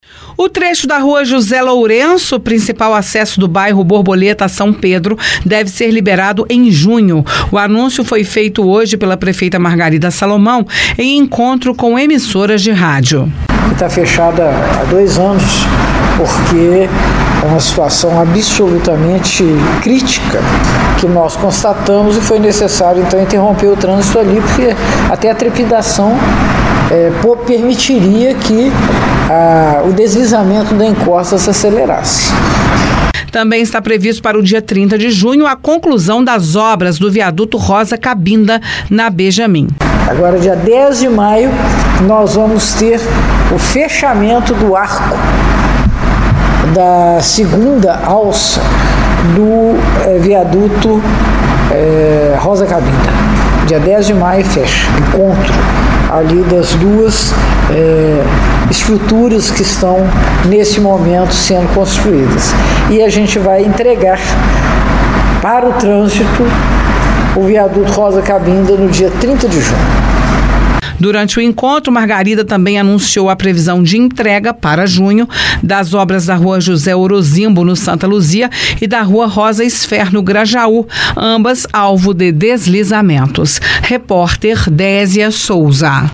Ela fez o anúncio durante uma conversa com emissoras de rádio pela manhã.